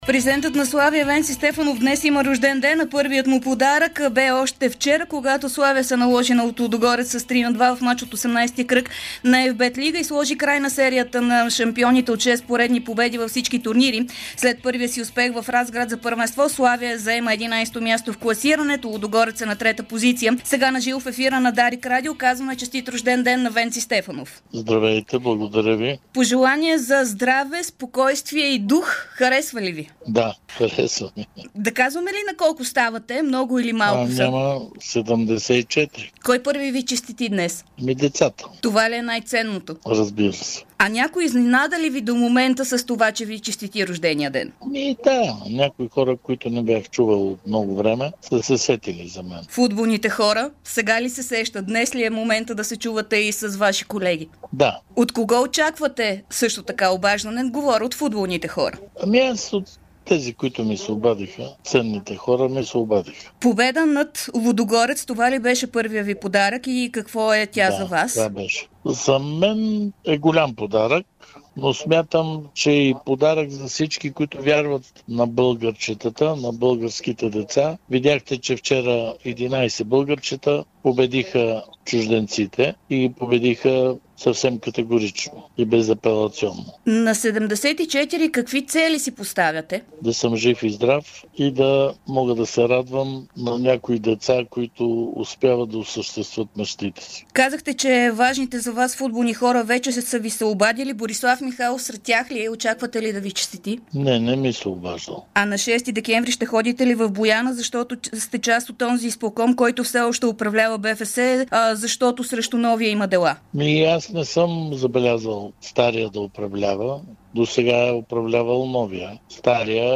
Президентът на Славия Венци Стефанов бе потърсен от Дарик радио по повод 74-тия му рожден ден, който празнува днес.